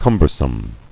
[cum·ber·some]